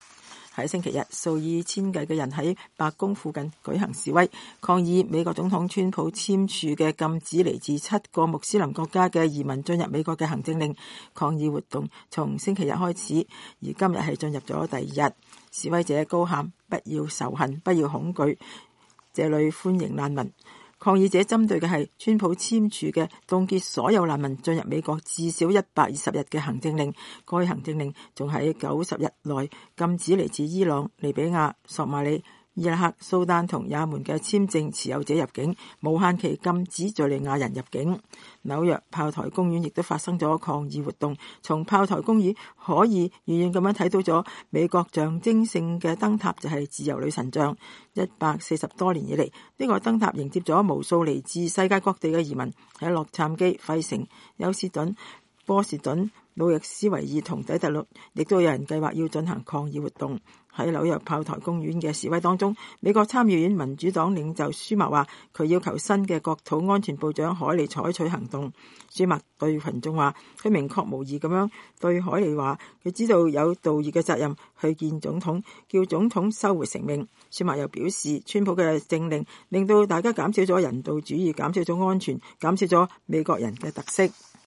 示威者高喊“不要仇恨，不要恐懼，這裡歡迎難民。”
在紐約砲台公園的示威中，美國參議院民主黨領袖舒默說，他要求新的國土安全部長凱利採取行動。